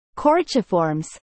Coraciiformes Pronunciation
Coraciiformes-Pronunciation.mp3